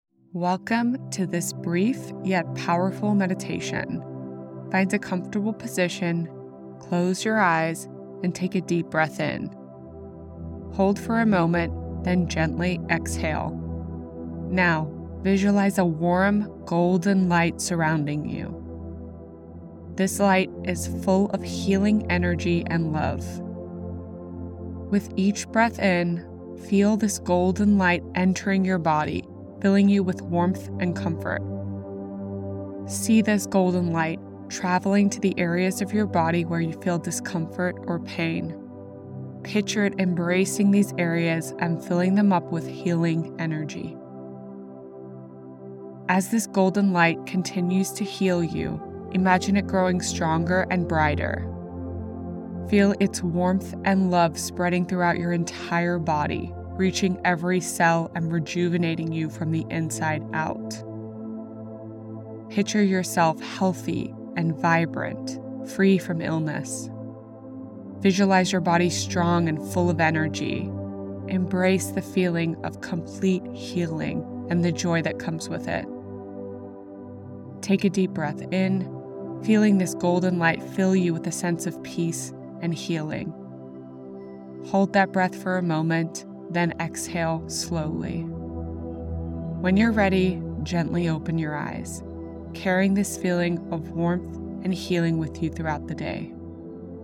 90-second Healing Visualization
In just 90 seconds, this healing visualization meditation provides a quick reset to refocus and calm your mind, perfect for starting your day or finding immediate relief throughout it.